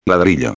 13/06/2011 ladrillo tijolo •\ [la·dri·llo] \• •\ Substantivo \• •\ Masculino \• Significado detalhado: Pieza de arcilla cocida, generalmente en forma de prisma rectangular, que se usa en la construcción.